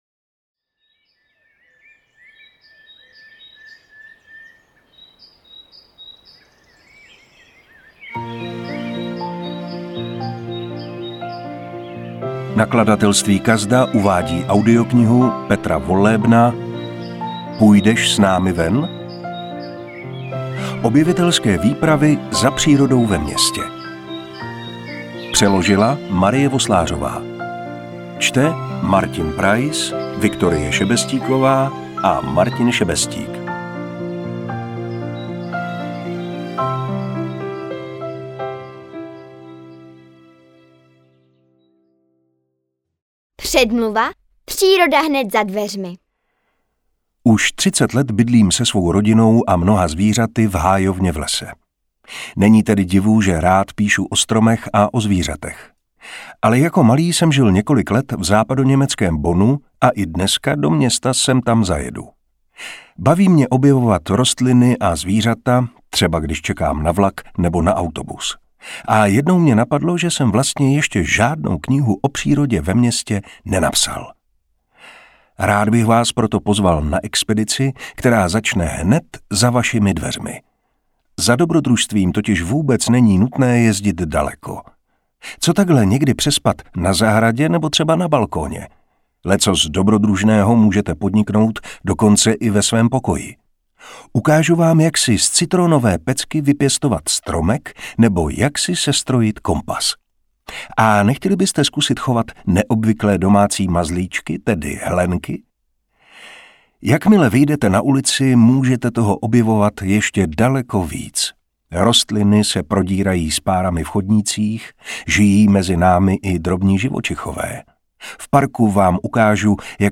Interpret:  Martin Preiss
Půjdeš s námi ven? – audiokniha pro malé přírodovědce.